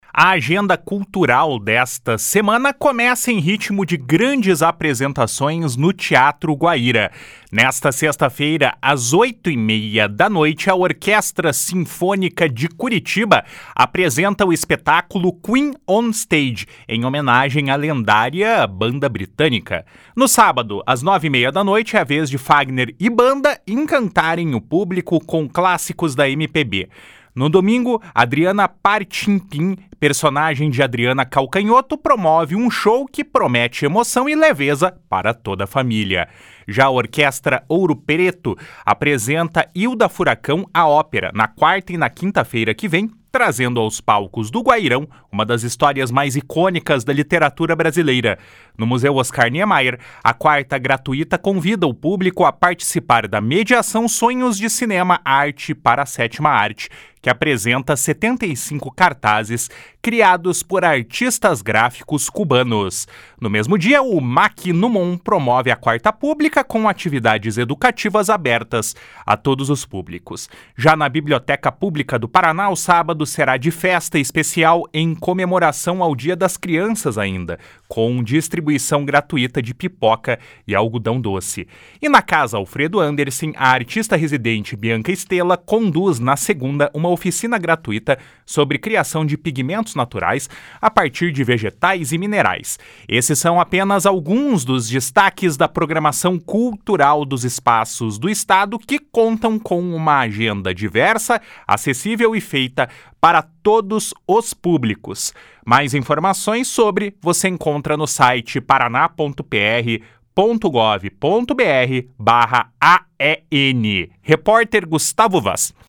AGENDA CULTURAL.mp3